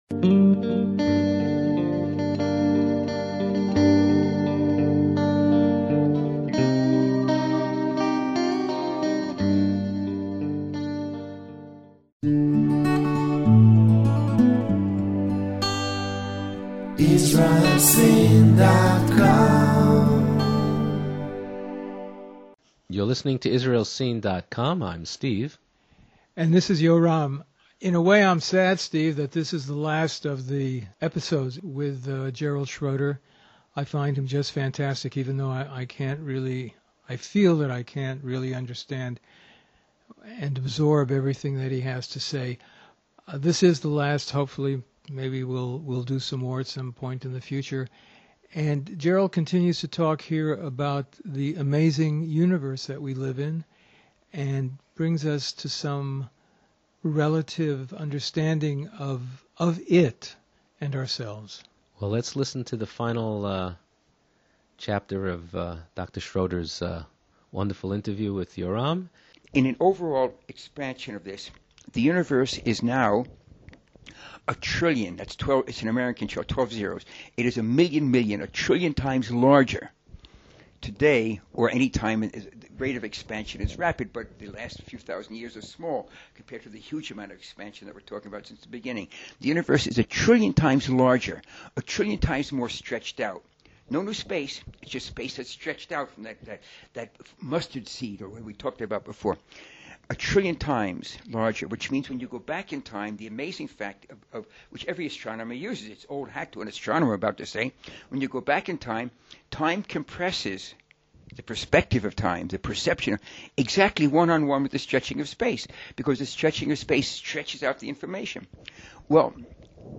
In this the last of the series of interviews, we range over the connections between the six days of creation as described in the Hebrew Bible and the cutting edge of scientific theory regarding the origins of out universe. We also discuss evolution; the amazing and exact unique placement in the cosmos of this planet that allows for life here. The Amazing Universe we live in.